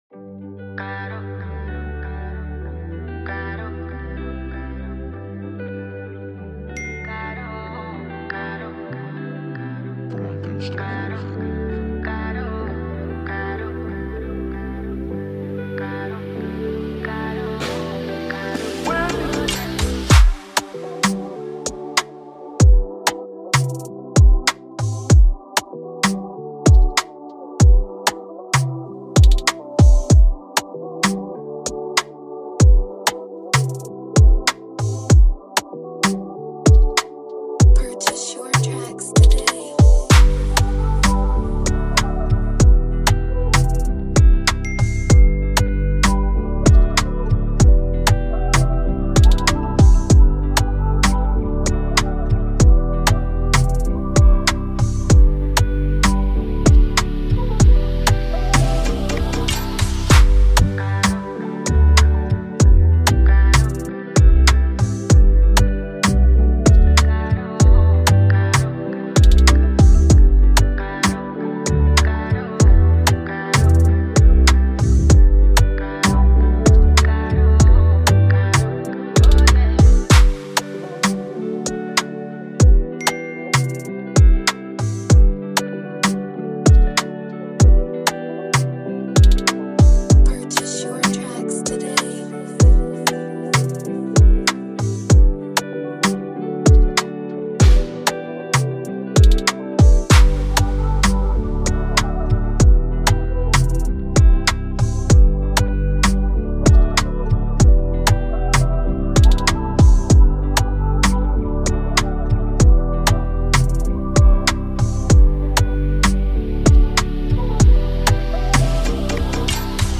Afrobeat style